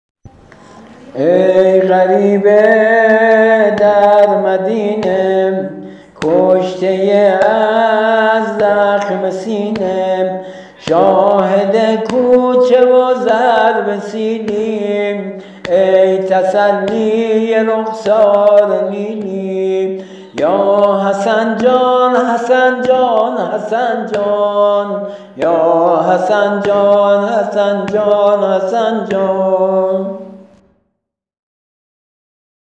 ◾نوحه امام حسن
◾نوحه پیامبر و امام رضا(ع)